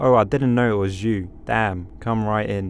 Voice Lines
Construction worker
Update Voice Overs for Amplification & Normalisation